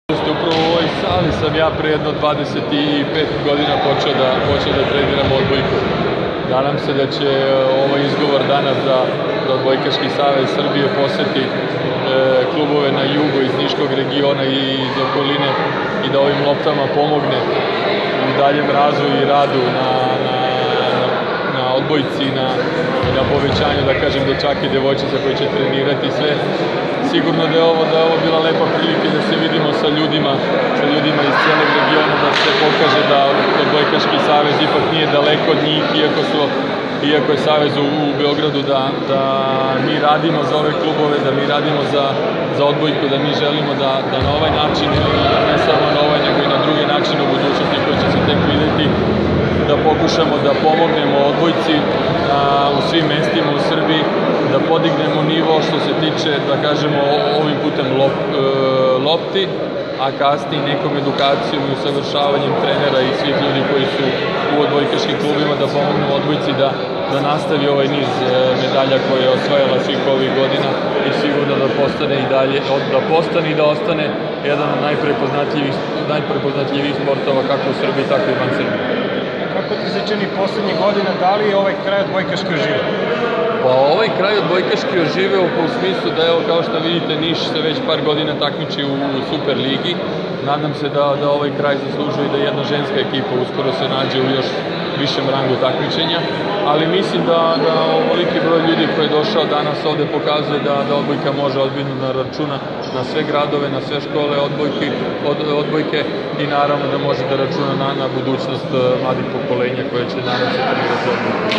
IZJAVA IVANA MILJKOVIĆA